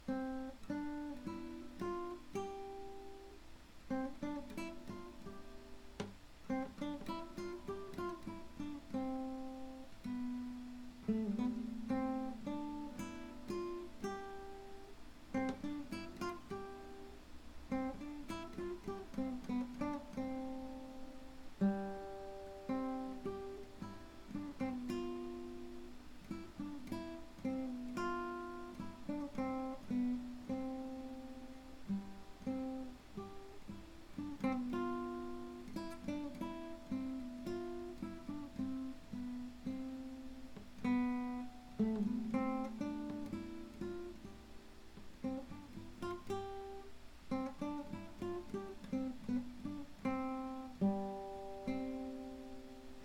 Classical guitar exercise practice.